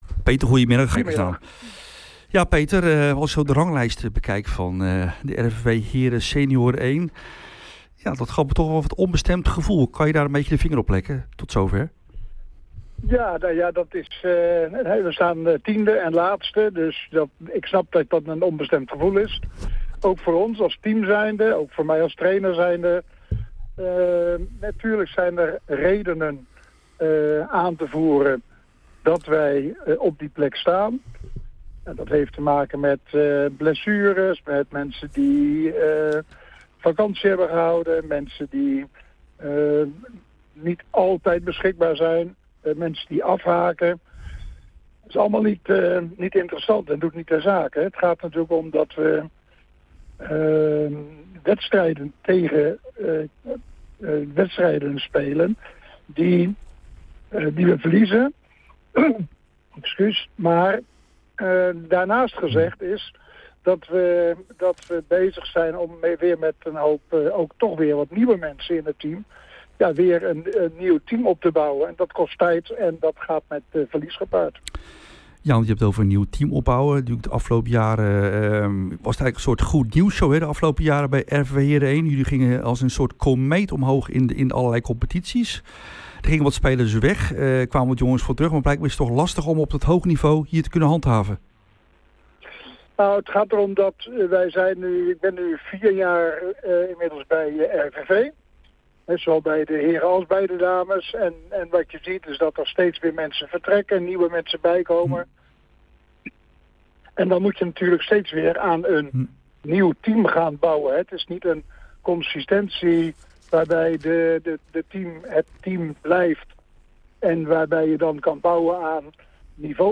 hier ook het interview.